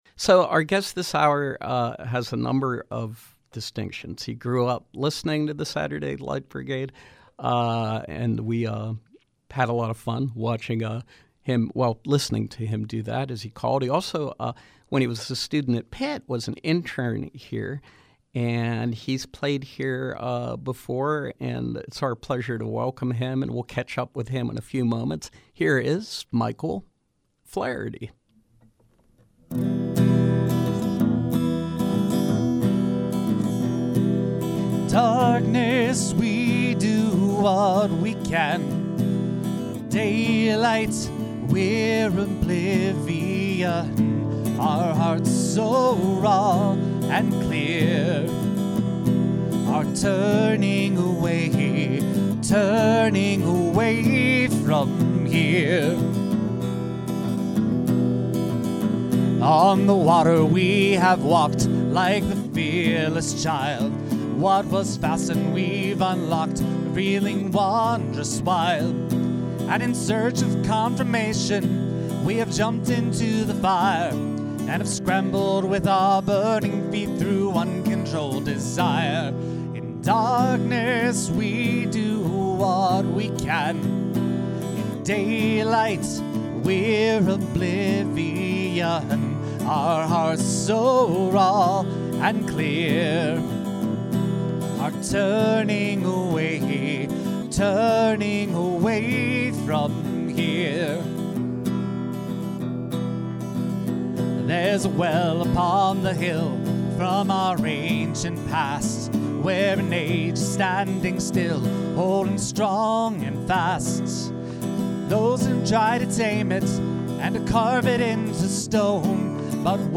Folk singer and balladeer